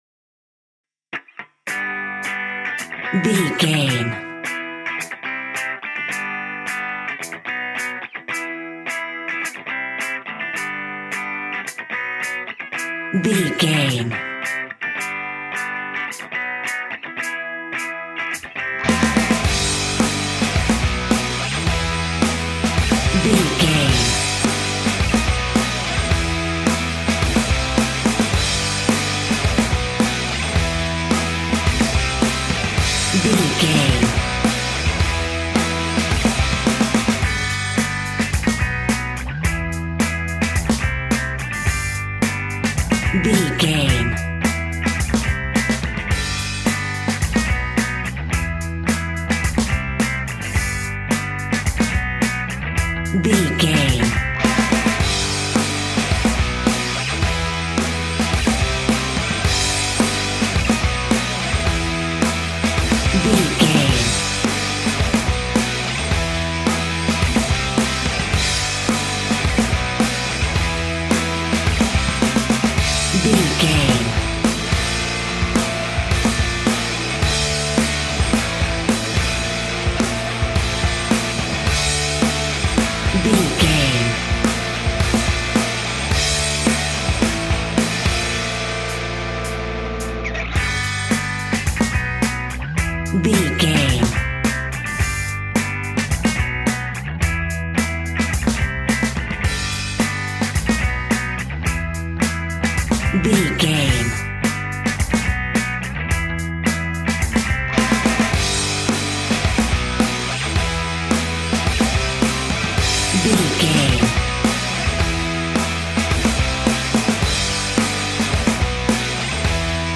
Epic / Action
Ionian/Major
hard rock
heavy metal
dirty rock
rock instrumentals
Heavy Metal Guitars
Metal Drums
Heavy Bass Guitars